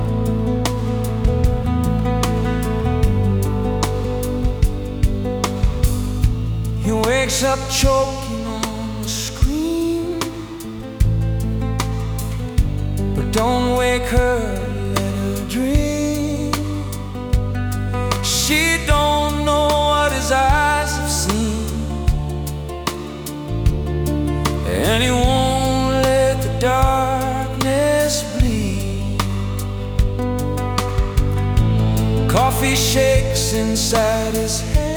Off-beat гитары и расслабленный ритм
Жанр: Регги